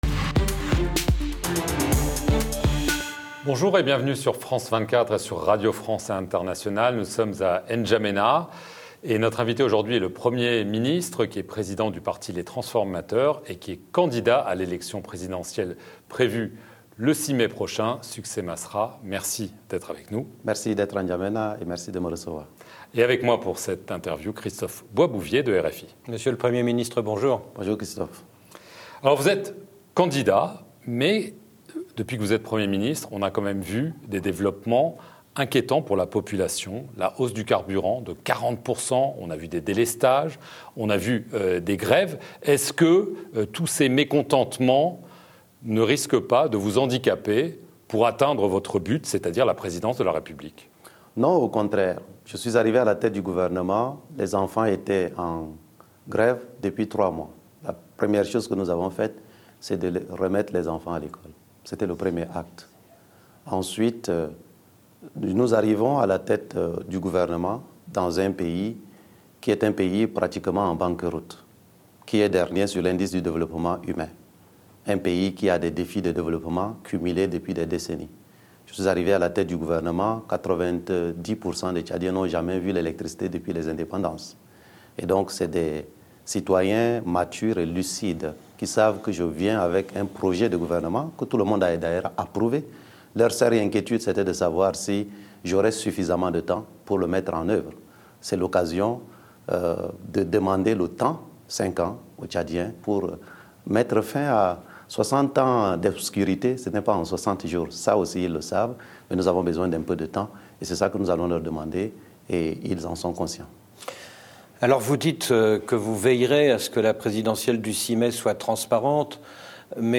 L'Entretien
Le chef du parti "Les Transformateurs" et Premier ministre de la transition du Tchad Succès Masra a répondu aux questions de France 24 et RFI, depuis la capitale tchadienne, N'Djamena.